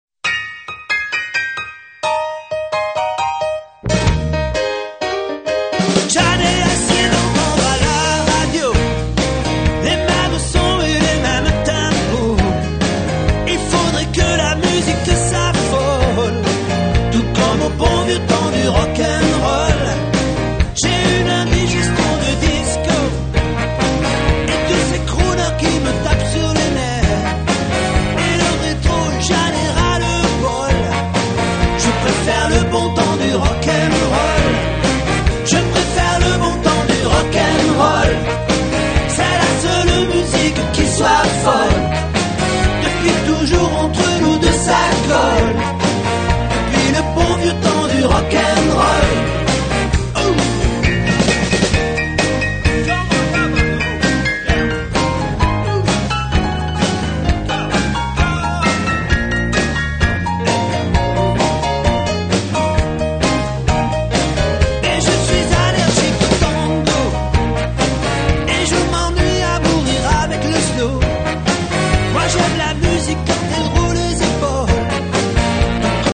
19 titres de rock n’ roll Francais revisit?s